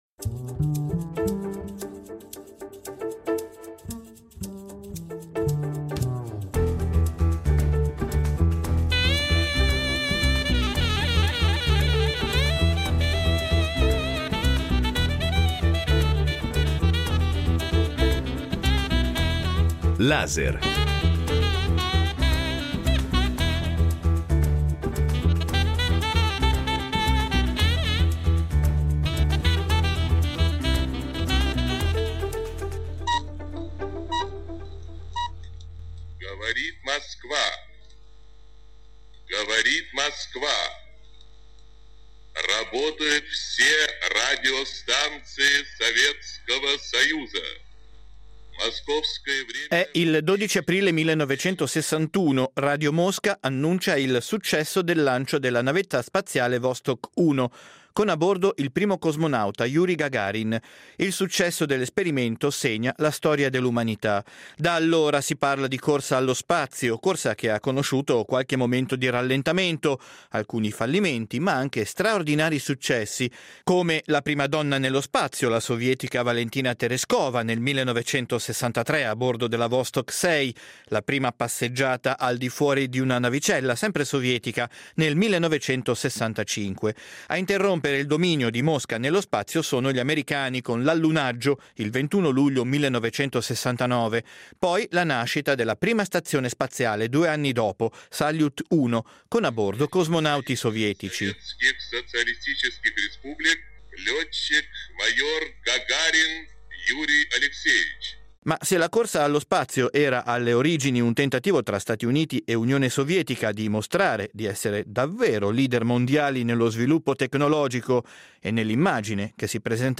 Poechali! Incontro con l'astronauta Samantha Cristoforetti